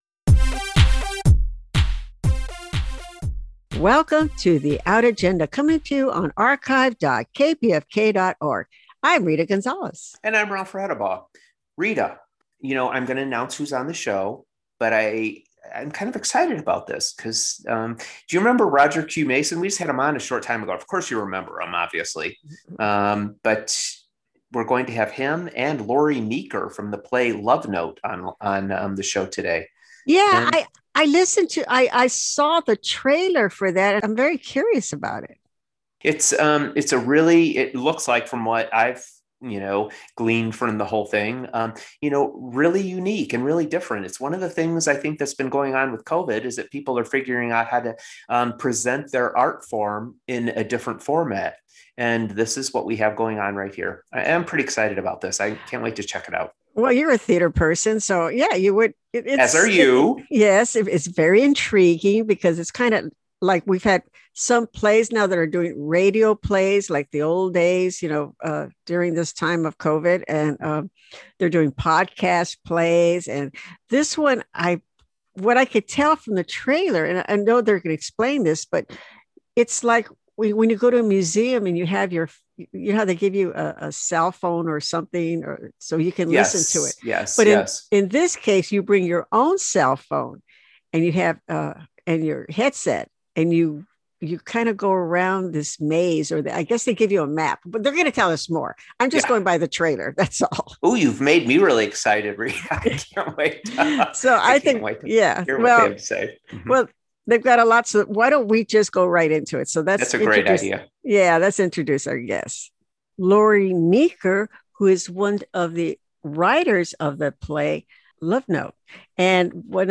Love Note – Features & Interview – Lucy Pollak Public Relations